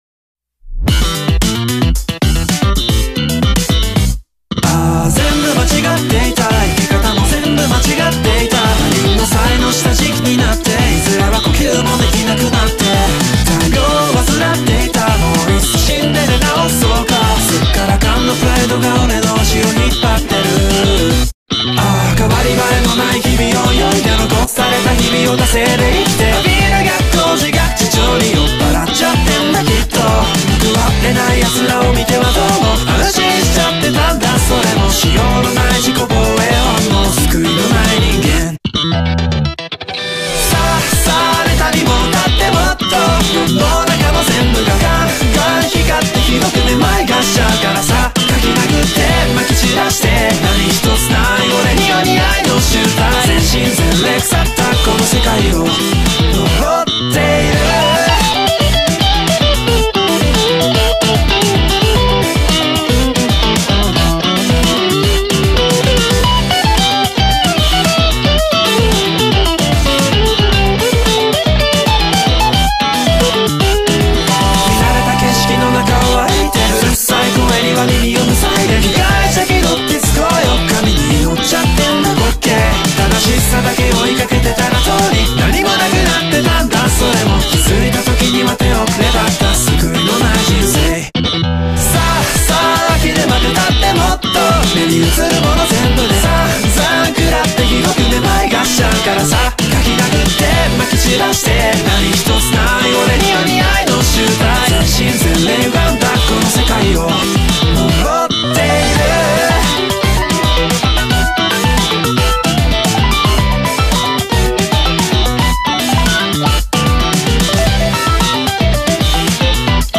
Chorus
Guitar Solo